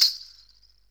One Short Sample Packs Free Download